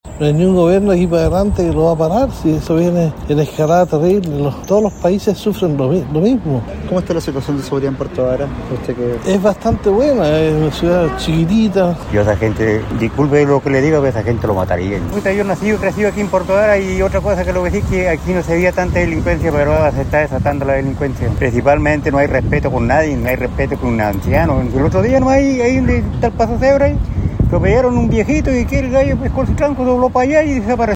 La sensación de inseguridad está disparada, según los vecinos. Así lo relataron varones que transitaban por el mismo sector de Puerto Varas.